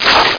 SLASH.mp3